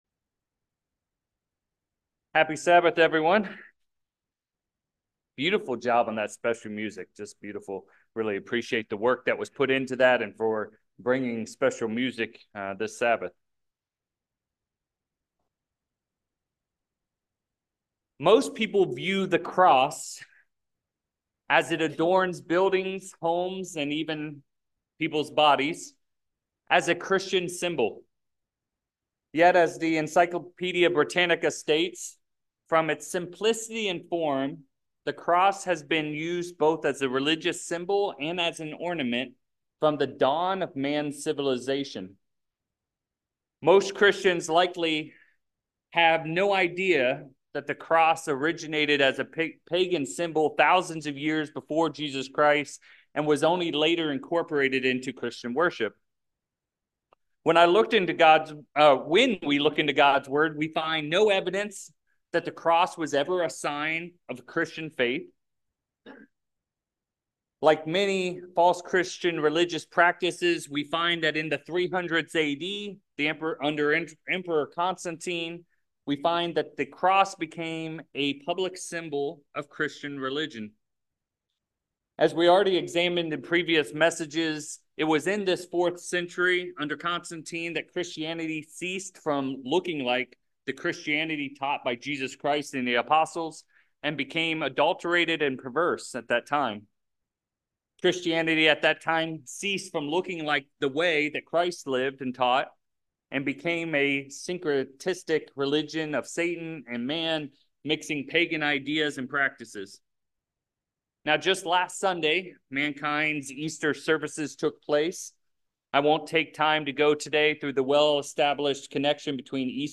What should the "cross" mean to True Christians? In this sermon, we look at what the Bible reveals about the cross of Christ!